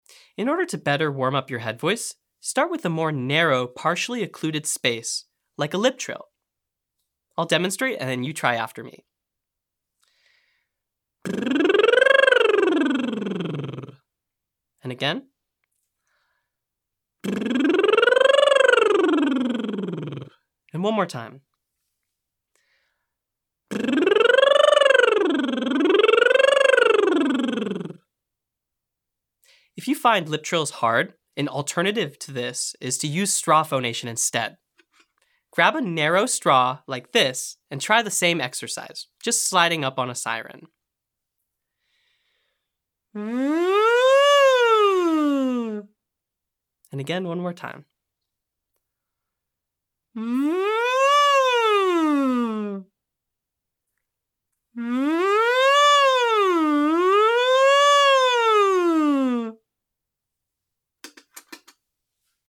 • Owl Hoot/woohoo cheer imitation to access your head voice.
• SOVT Lip Trill or straw phonation (1-8-1) to warm up head voice using a partially occluded space.
• Siren slide on "Wee" to stretch and develop falsetto range.